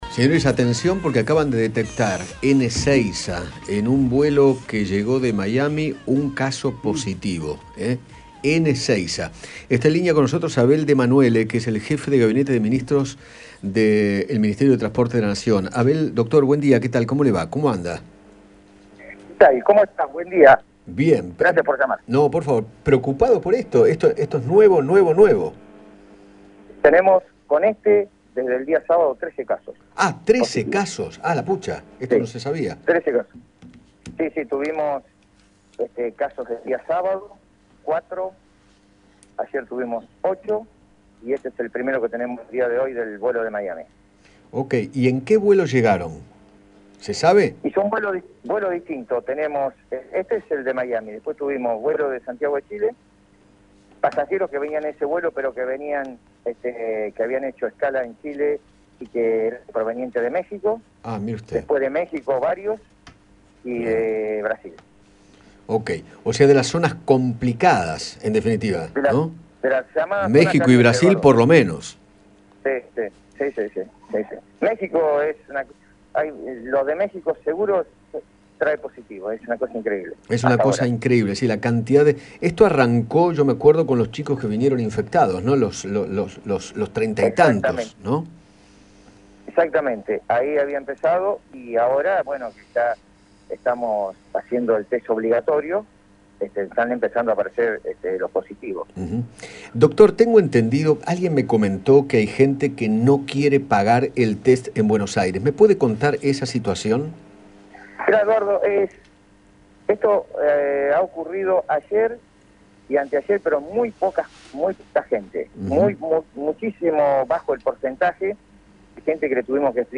Abel de Manuele, jefe de Gabinete del Ministerio de Transporte, habló con Eduardo Feinmann sobre la cantidad de positivos de coronavirus que llegaron a Ezeiza desde diversos vuelos y contó que “desde el sábado, con este nuevo caso, son 13 las personas que dieron positivo”. Además, se refirió a la obligatoriedad de hisoparse al arribar al país.